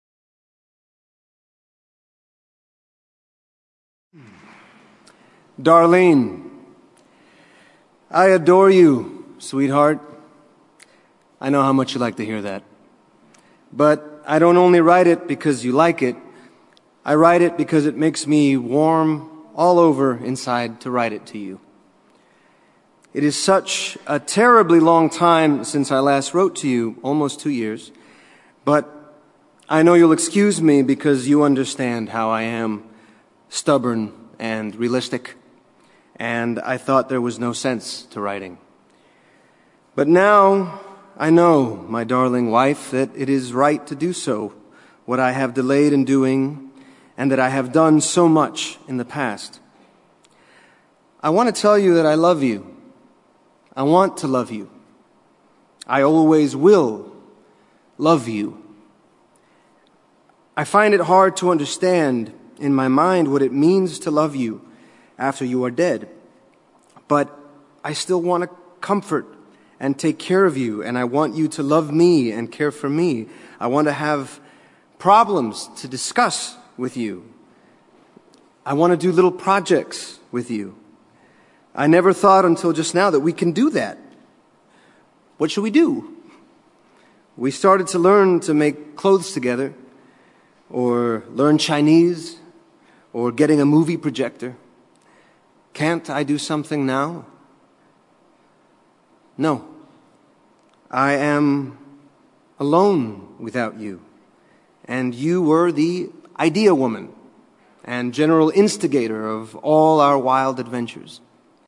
在线英语听力室见信如晤Letters Live 第16期:'奥斯卡伊萨克'读信:我爱我的妻子,她去世了(1)的听力文件下载,《见信如唔 Letters Live》是英国一档书信朗读节目，旨在向向书信艺术致敬，邀请音乐、影视、文艺界的名人，如卷福、抖森等，现场朗读近一个世纪以来令人难忘的书信。